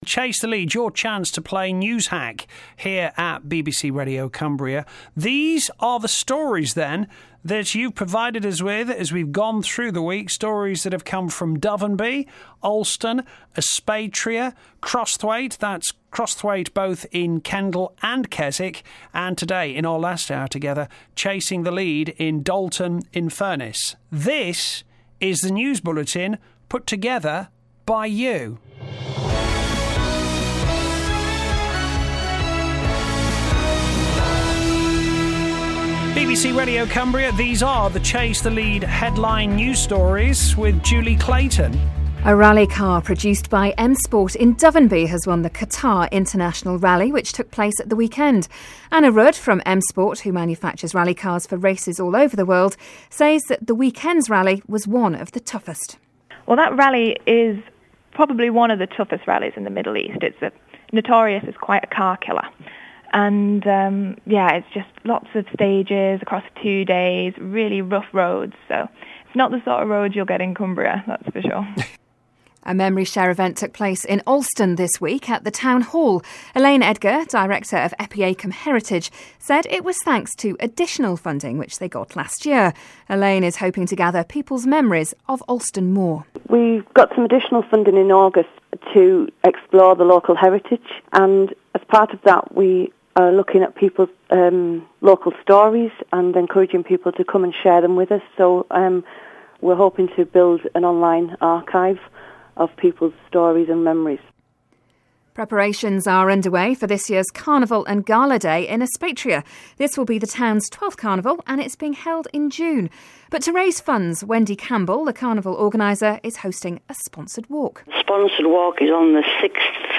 These are the stories you provided in Dovenby, Alston, Aspatria, Crosthwaite both in Kendal and Keswick, and Dalton in Furness - This is the news bulletin put together by you!